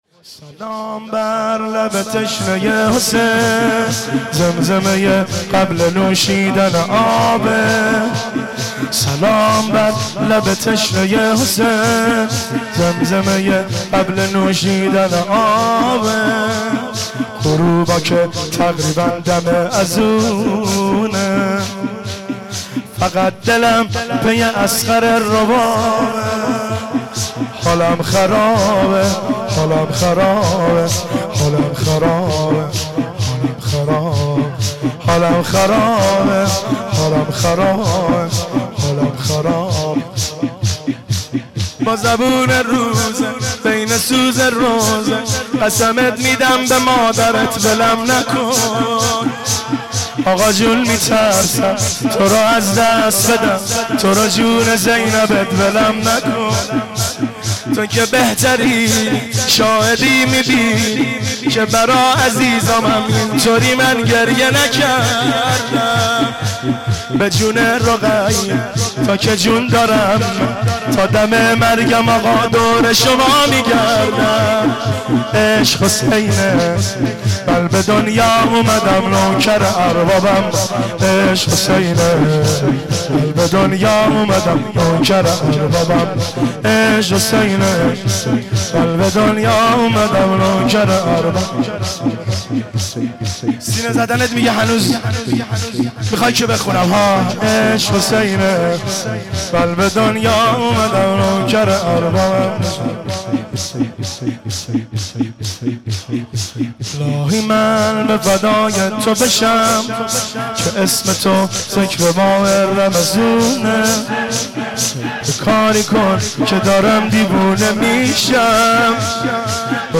مداح
مناسبت : شب بیست و چهارم رمضان
قالب : شور